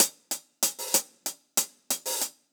Index of /musicradar/ultimate-hihat-samples/95bpm
UHH_AcoustiHatB_95-01.wav